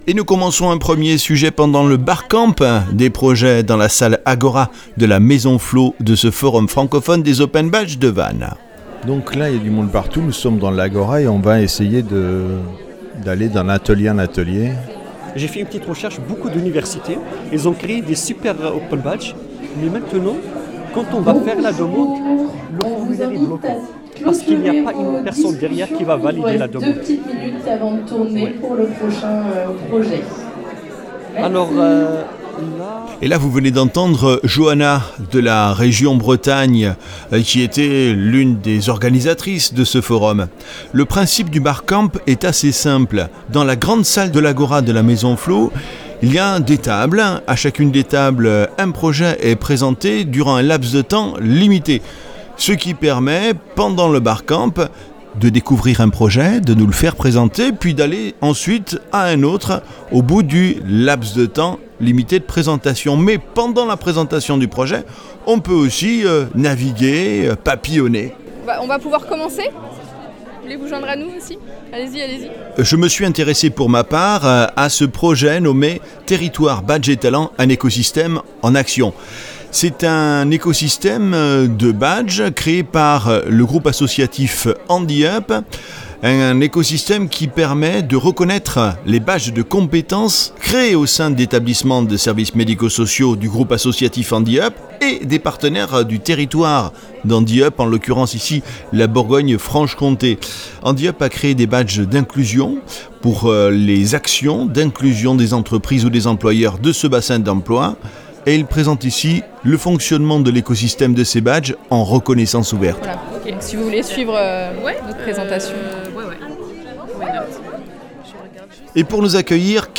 Dans cet épisode enregistré au cœur de l'effervescence du forum francophone des open badges, Radio Badges vous emmène à la découverte d'une initiative inspirante.